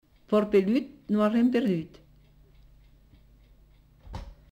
Aire culturelle : Comminges
Effectif : 1
Type de voix : voix de femme
Production du son : récité
Classification : proverbe-dicton